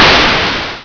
playerhit.wav